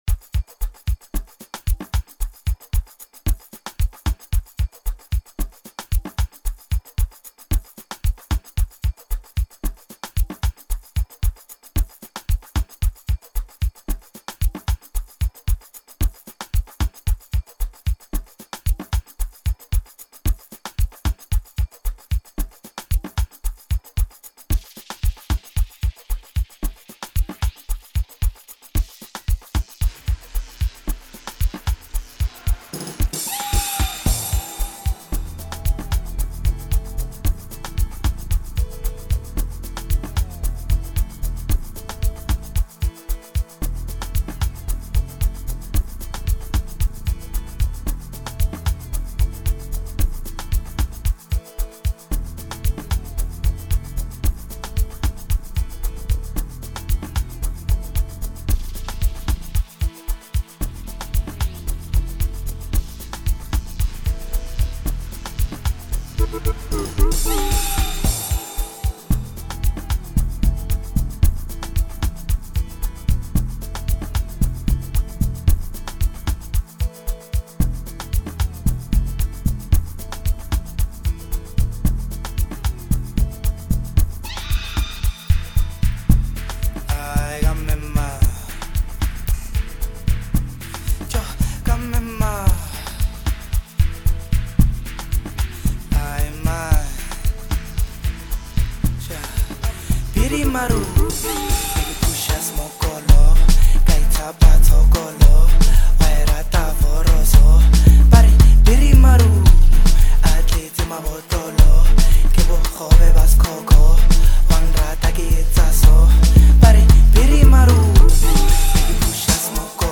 an amapiano artist